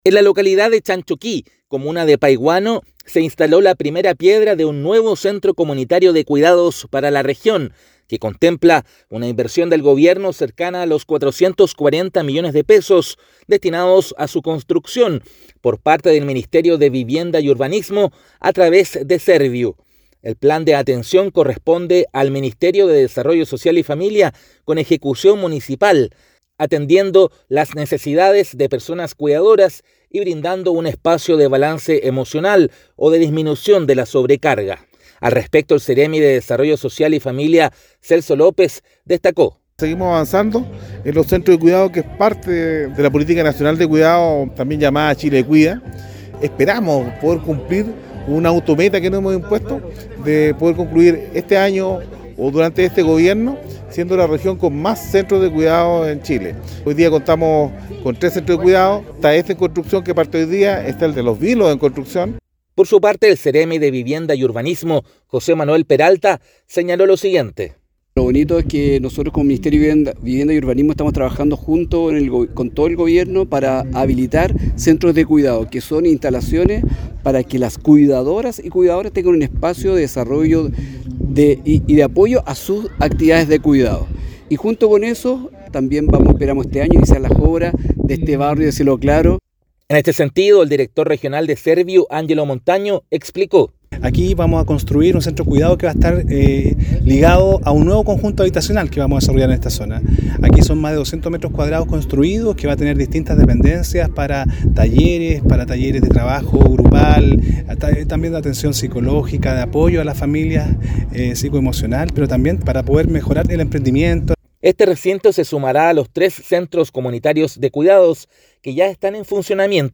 DESPACHO-RADIAL-Centro-Comunitario-de-Cuidados-Paihuano.mp3